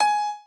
admin-fishpot/b_piano1_v100l4o6gp.ogg at main